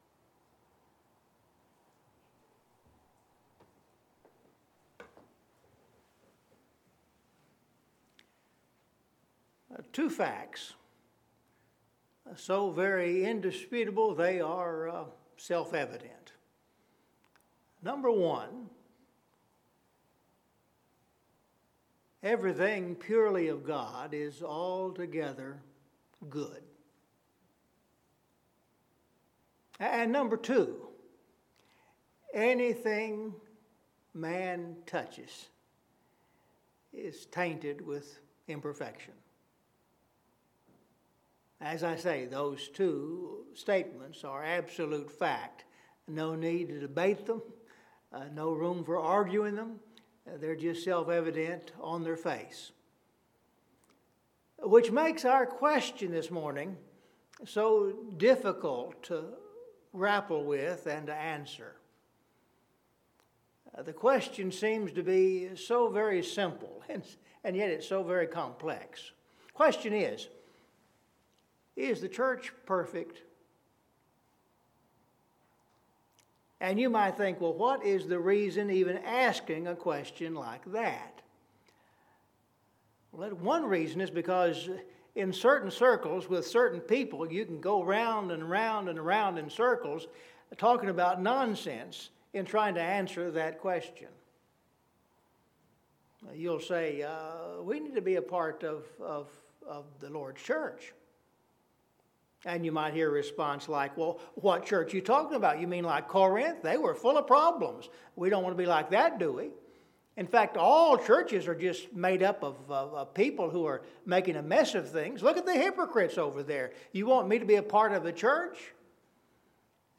Scripture Reading – Ephesians 5:25-27